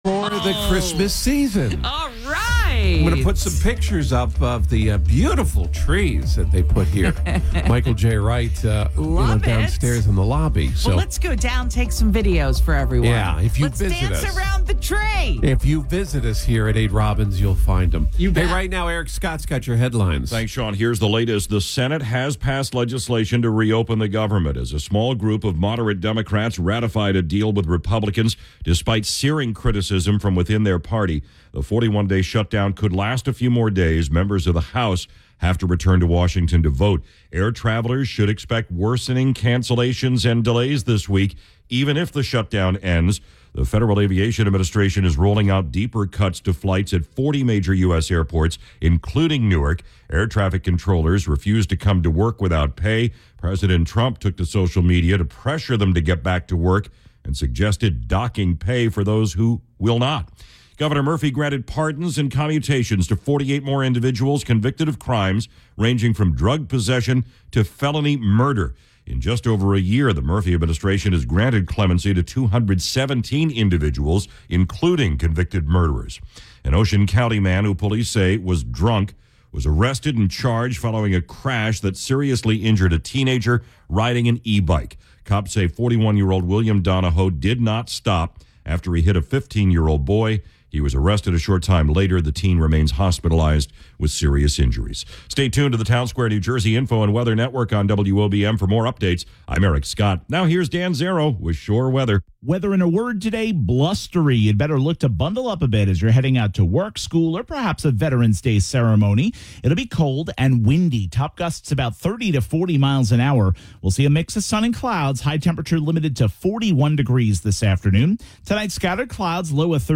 Genres: News